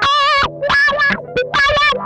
MANIC WAH 9.wav